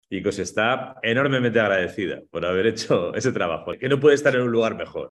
El pasado 19 de abril, los célebres autores Javier Cercas y Lorenzo Silva compartieron con los lectores y lectoras ciegos de los más de 30 Clubes de Lectura (braille y sonoro) que la ONCE tiene en el país una tarde en la que, ambos escritores conversaron y compartieron sus experiencias literarias en el ya tradicional encuentro literario online con motivo del Día del Libro.